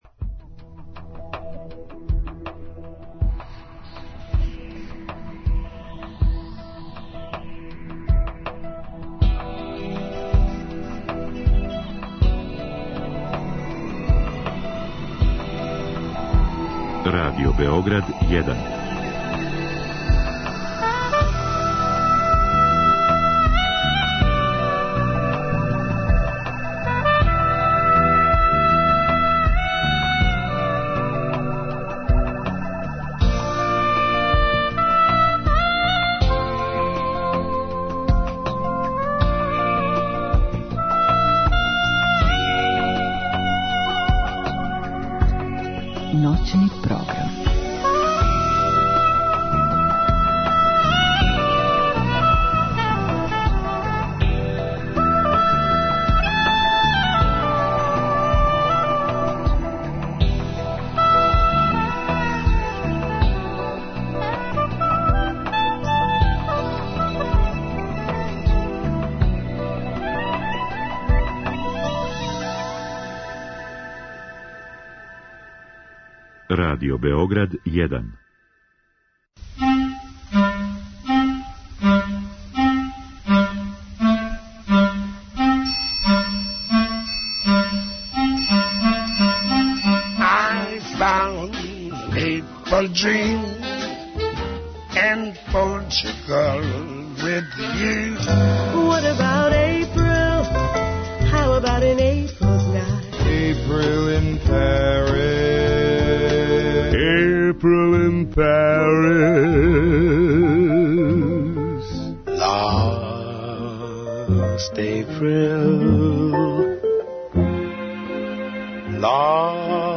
У последњем сату - концертни снимци.